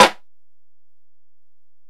Snare (35).wav